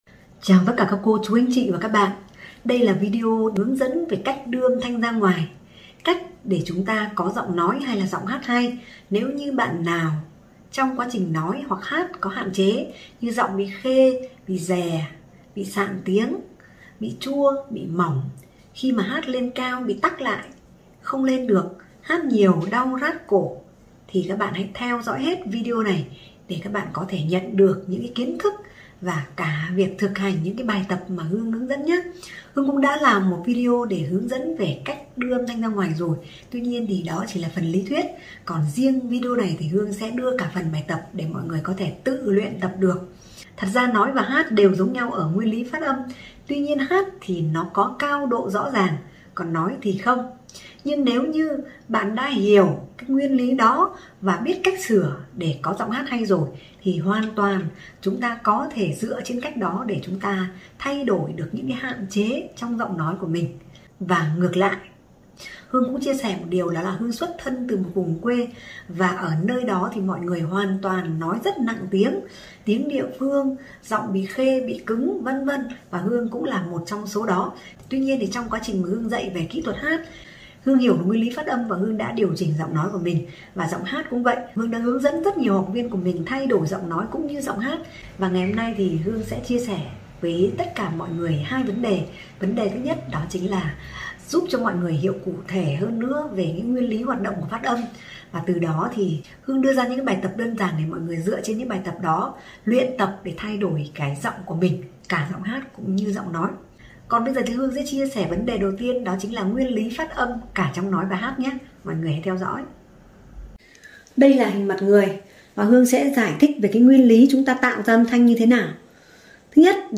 Bài Luyện để giọng nói, giọng hát hay.
Bài Luyện để có giọng nói, giọng hát hay.